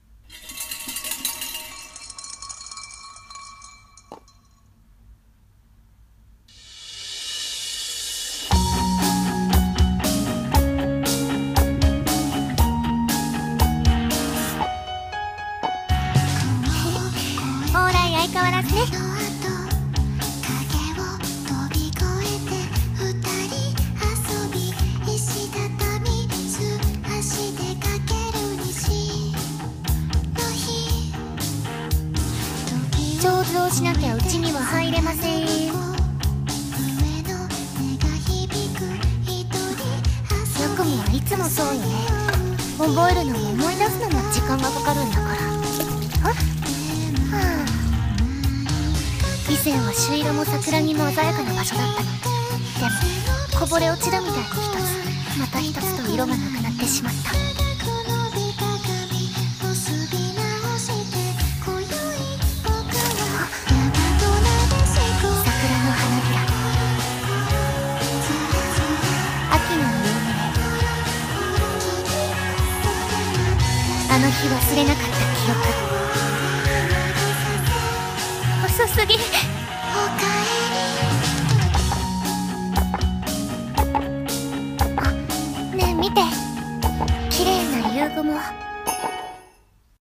CM風声劇｢懐古神社